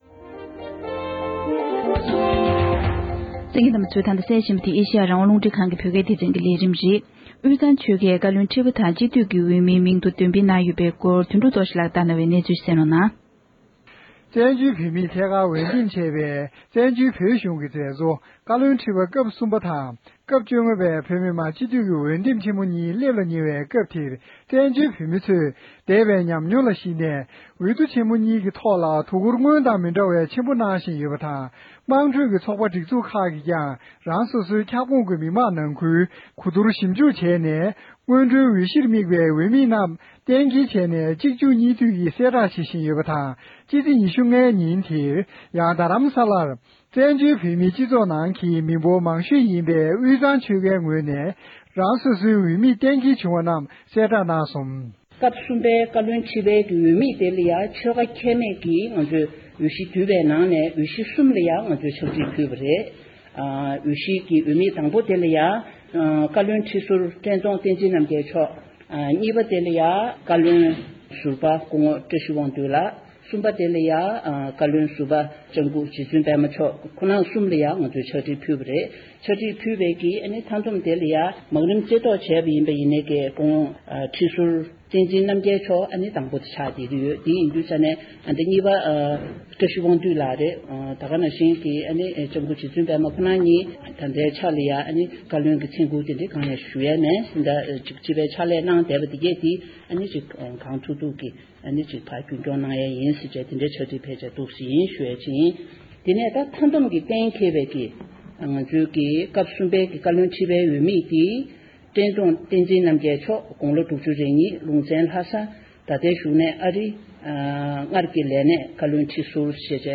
ས་གནས་ས་ཐོག་ནས་བཏང་འབྱོར་བྱུང་བ་ཞིག་ལ་གསན་རོགས༎